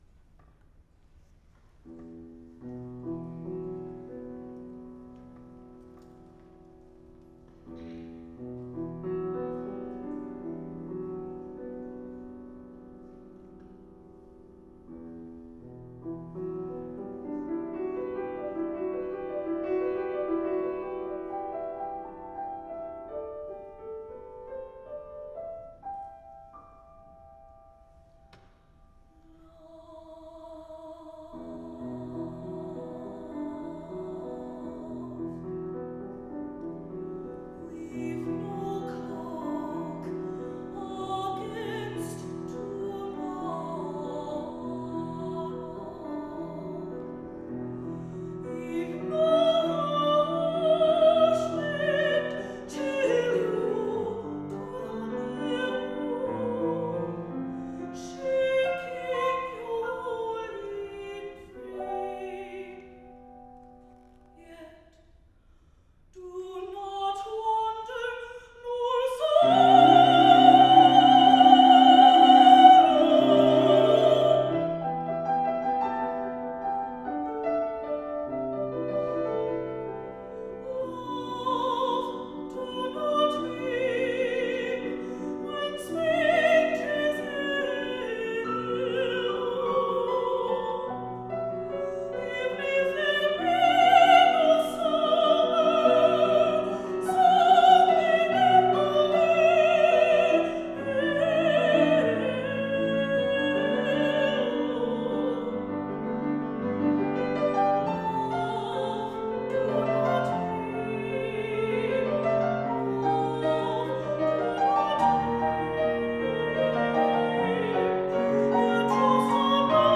for Voice and Piano (1974, rev. 1986)